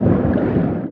Sfx_creature_pinnacarid_swim_fast_02.ogg